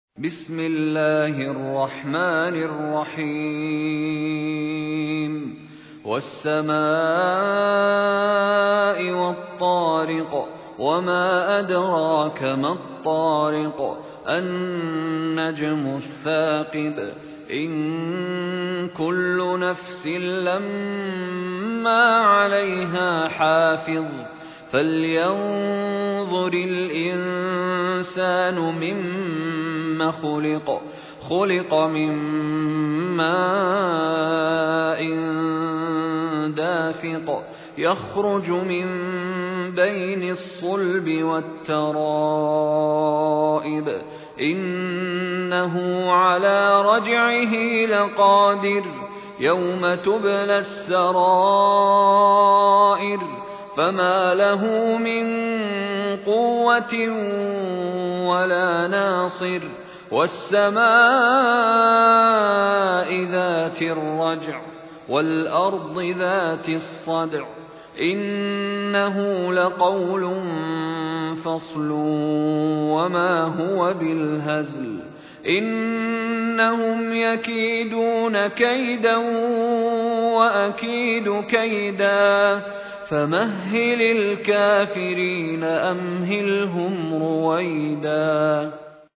مشاري بن راشد العفاسي المصحف المرتل - حفص عن عاصم - الطارق